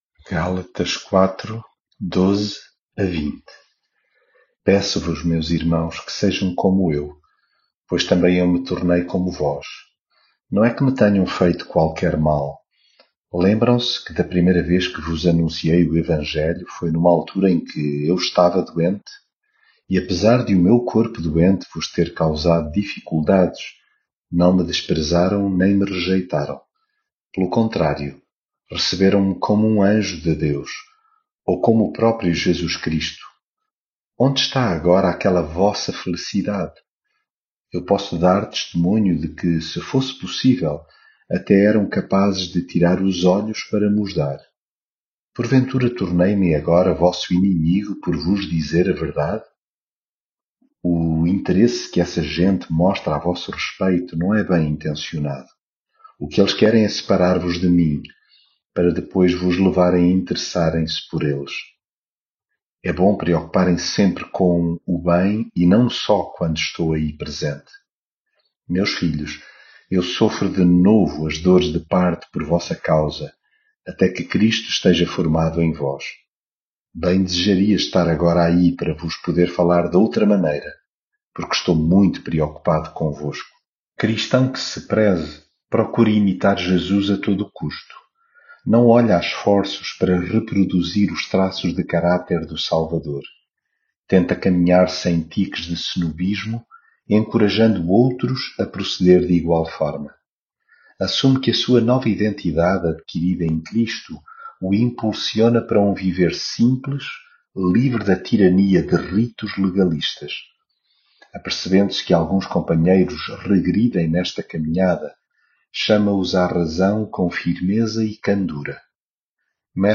devocional gálatas leitura bíblica E, apesar de o meu corpo doente vos ter causado dificuldades, não me desprezaram nem me rejeitaram.